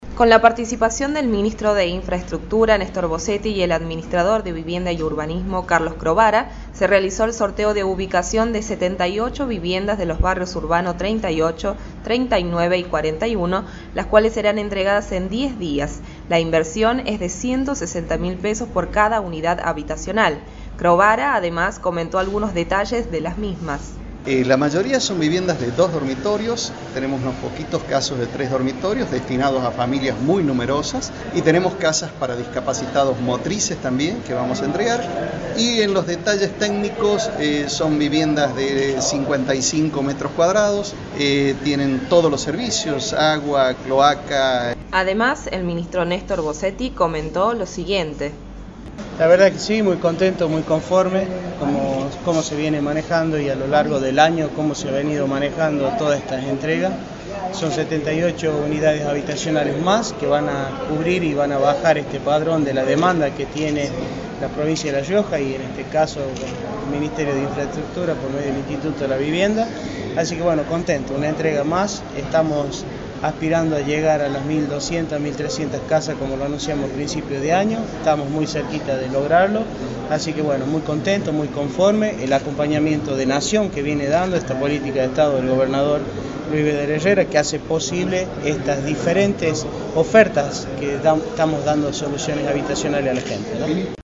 por Radio Independiente